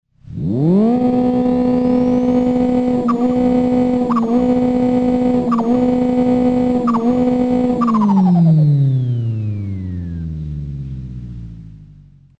I added a louder modded Flutter DumpValve.
vx sample.mp3 - 198 KB - 251 views